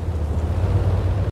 enginestop.ogg